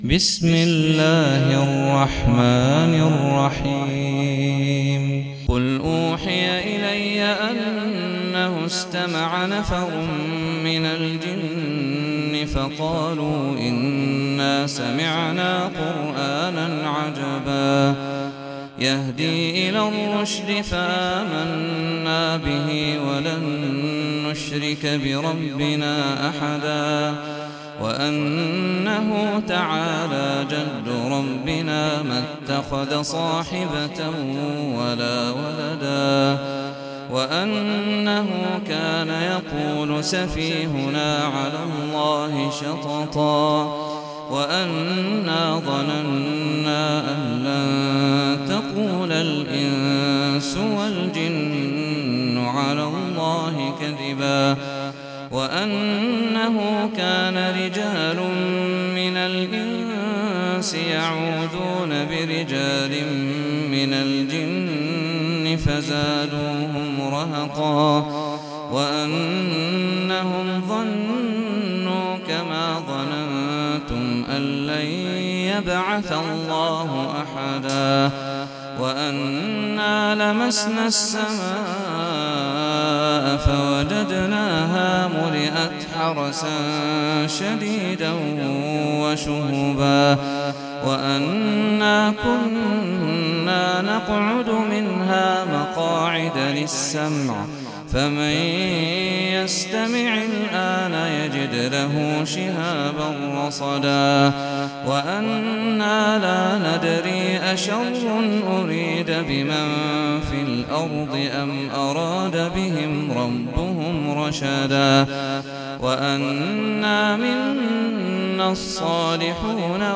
سورة الجن - صلاة التراويح 1446 هـ (برواية حفص عن عاصم
جودة عالية